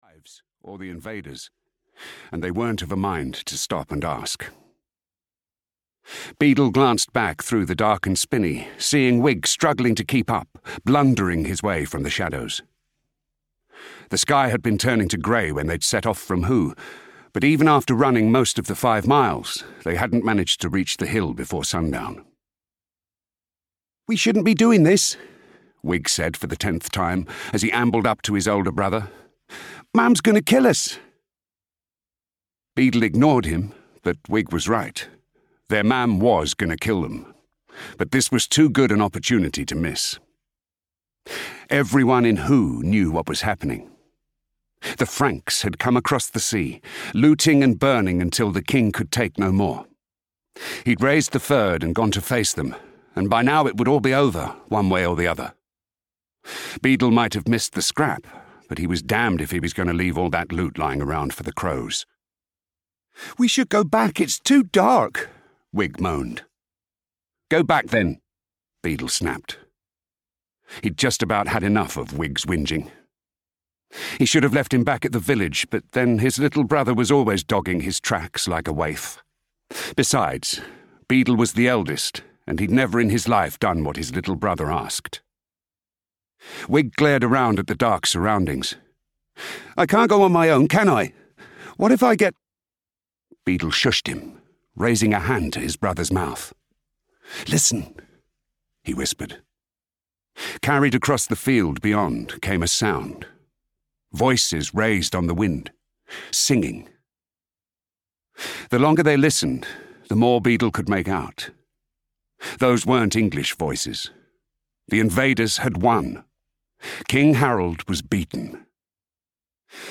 Audio knihaOath Bound (EN)
Ukázka z knihy